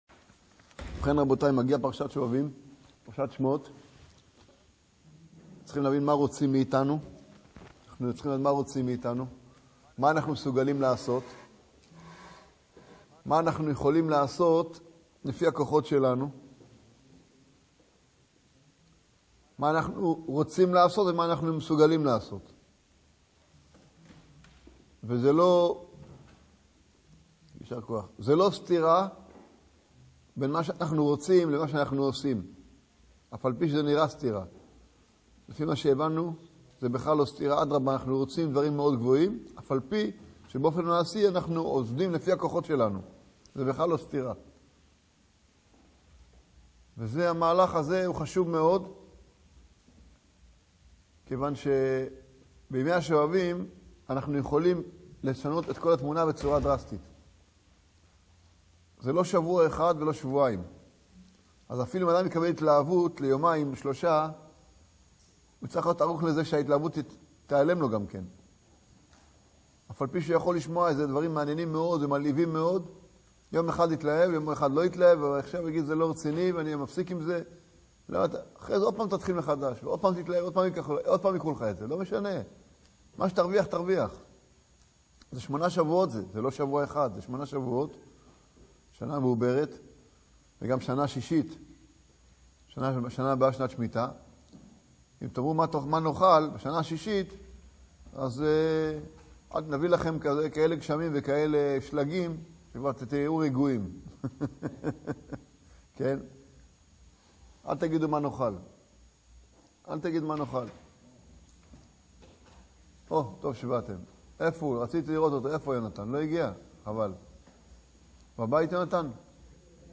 שובבים, תיקון הברית, תיקון הכללי, שיעור מדהים